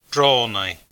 Pronunciation[ˈrˠɔːnaj]